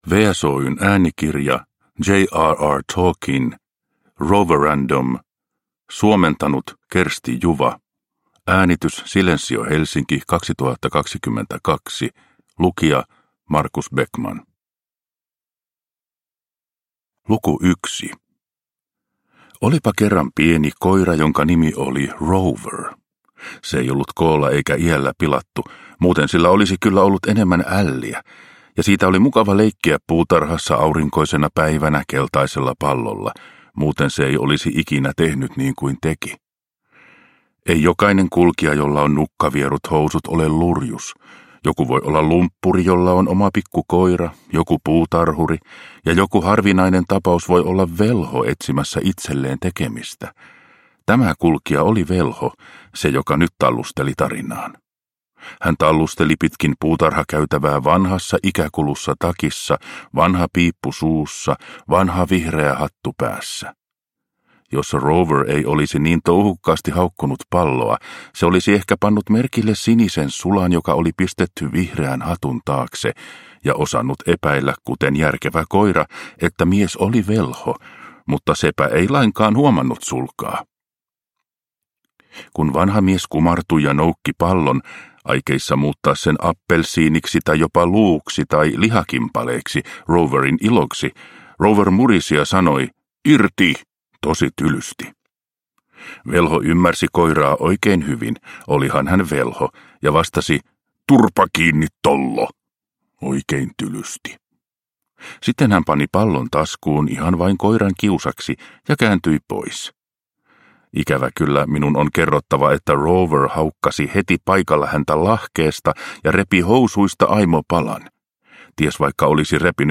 Roverandom – Ljudbok – Laddas ner